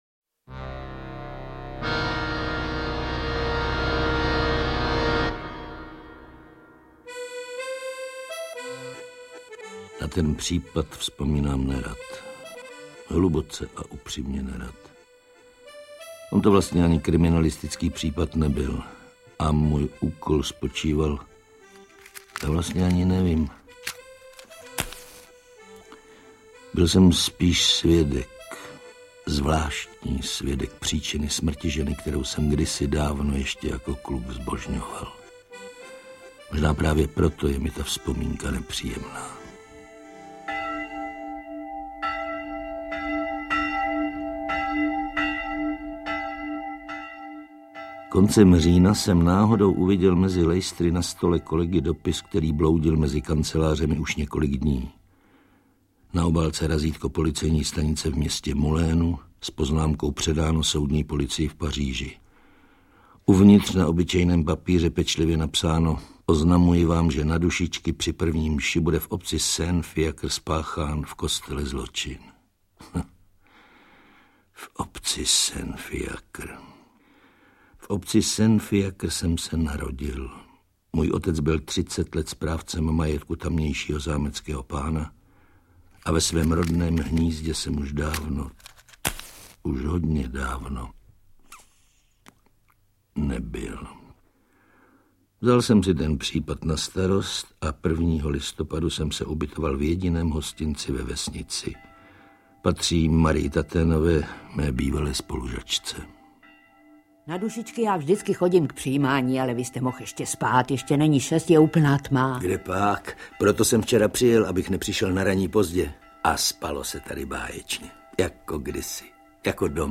Interpreti:  Ota Sklenčka, Josef Somr, Josef Vinklář
Tentokrát v podání Josefa Vinkláře, Josefa Somra a Oty Sklenčky.
CD 1 Případ Saint-Fiacre – v roli Maigreta Josef Vinklář
CD 2 Maigret a případ Cecilie – v roli Maigreta Josef Somr